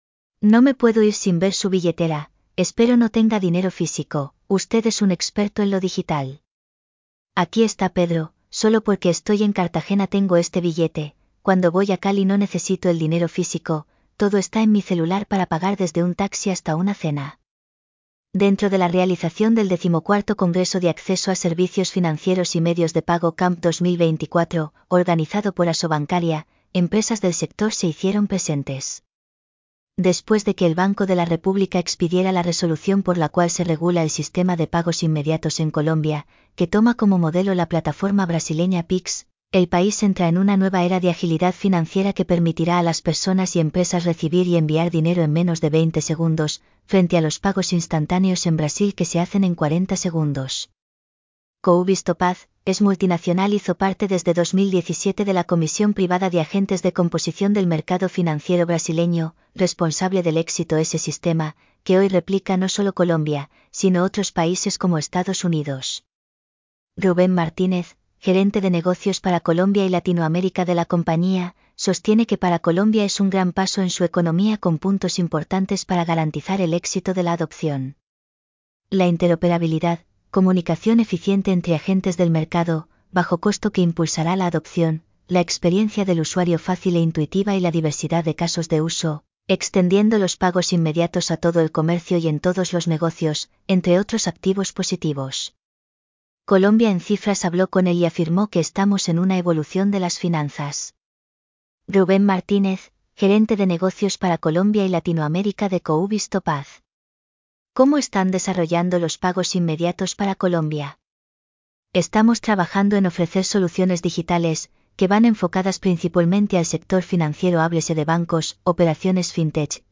Text-to-Speech-_3_.mp3